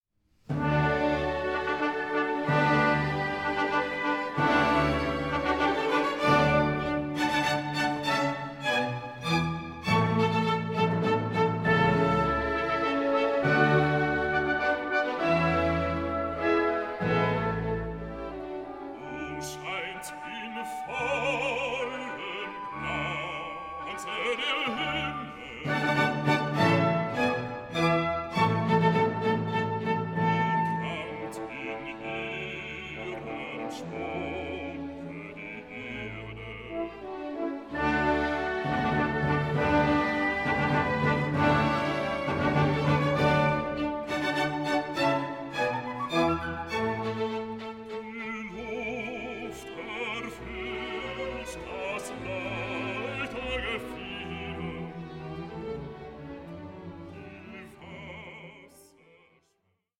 Classical transparency and Romantic drive